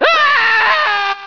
lots of screaming scientists
scream3.ogg